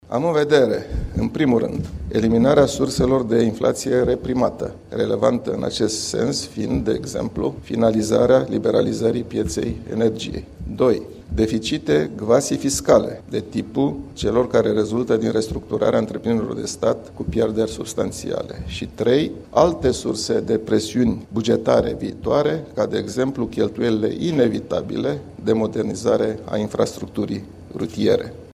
Aflat la conferinţa intitulată RISC DE ŢARĂ, oficialul Băncii Naţionale a atras atenţia că adoptarea monedei euro este complicată de procesul de reaşezare a raporturilor între principalele valute ale lumii.